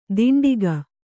♪ diṇḍiga